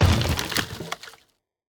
car-wood-impact-03.ogg